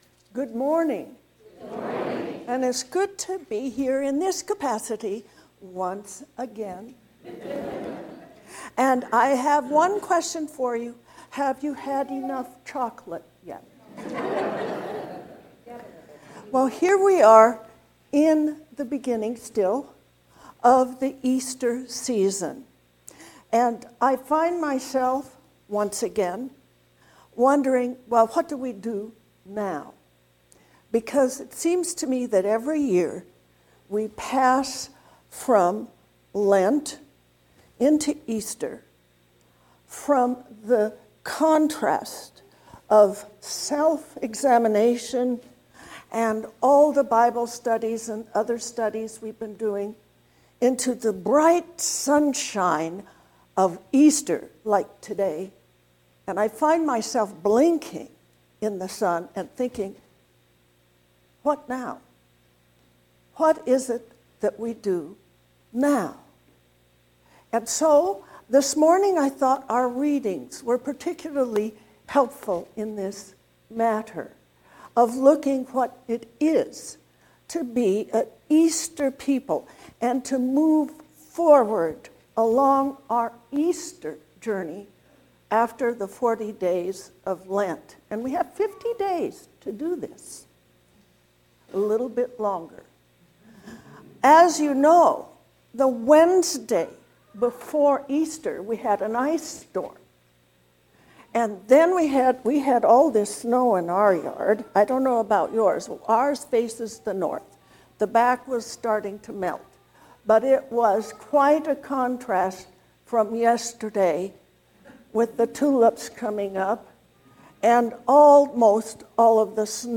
What comes next? A sermon for the 2nd Sunday of Easter.